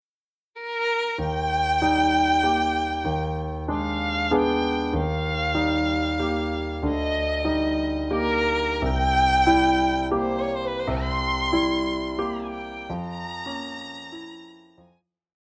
古典
鋼琴
獨奏與伴奏
有主奏
有節拍器